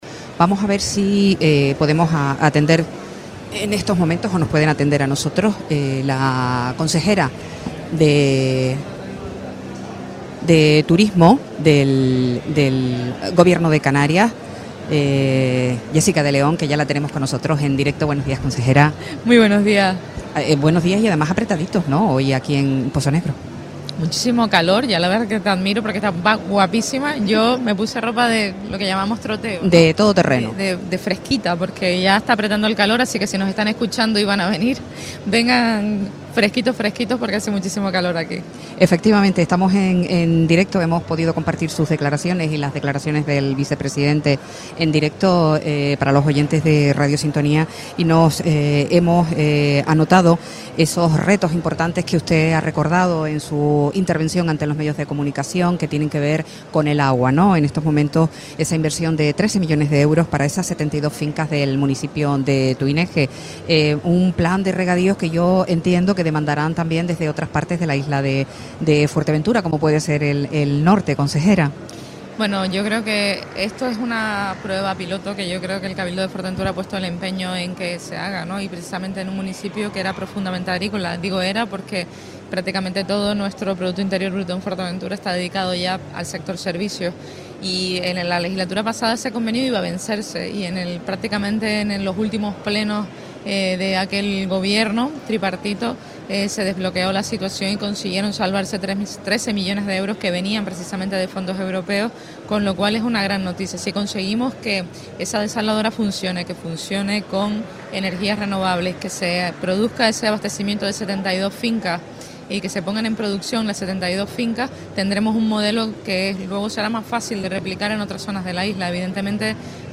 Entrevistas
La consejera de Turismo y Empleo del Gobierno de Canarias, Jessica de León visita el set de Radio Sintonía y muestra sus impresiones de la 38ª edición de Feaga